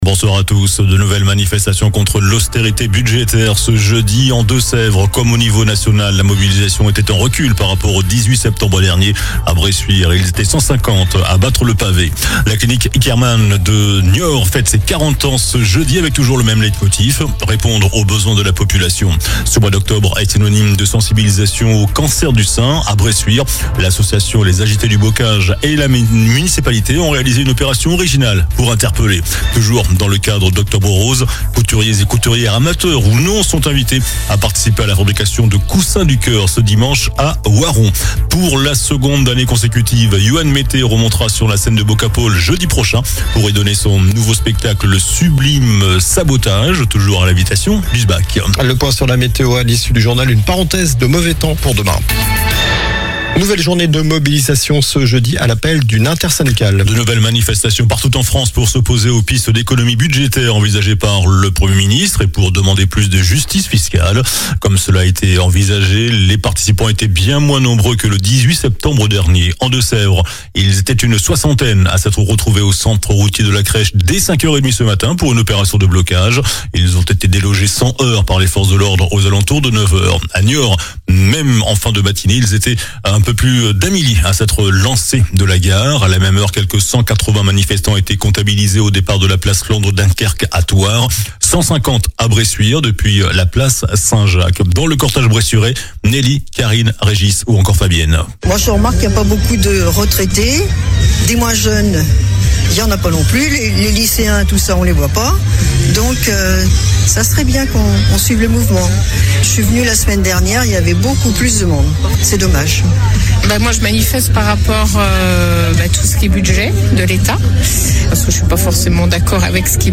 JOURNAL DU JEUDI 02 OCTOBRE ( SOIR )